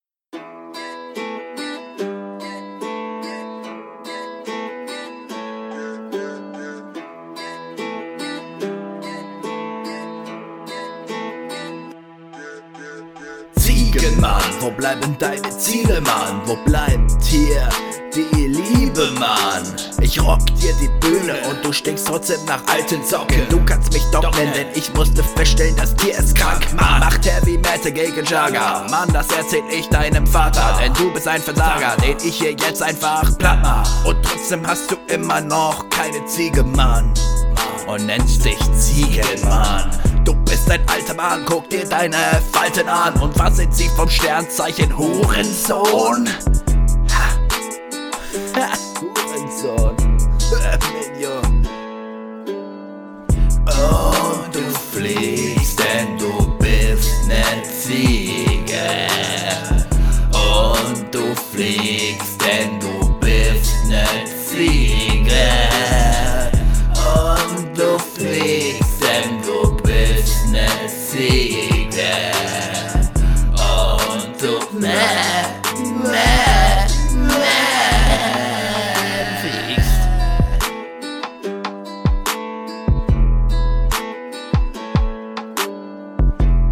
Ich weiß nicht, ob das "yea" zum Beat gehört, aber klingt richtig chillig.
Ganz cooler Beat.